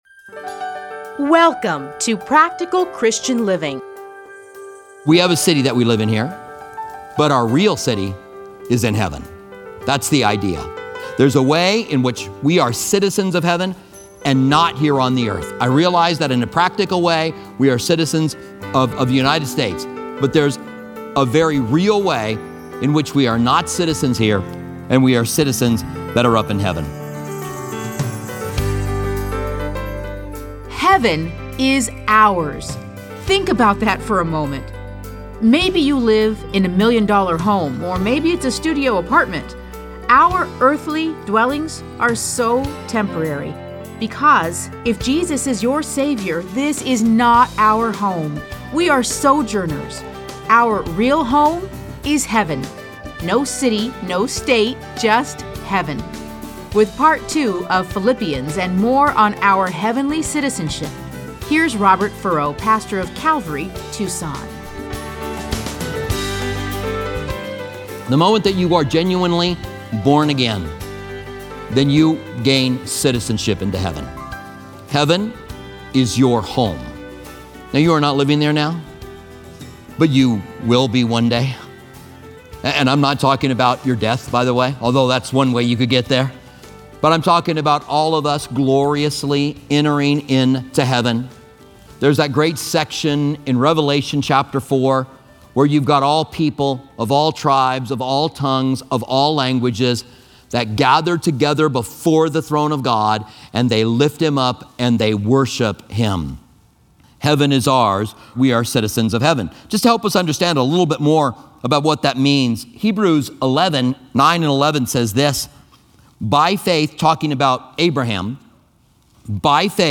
Listen to a teaching from A Study in Philippians 3:17-21.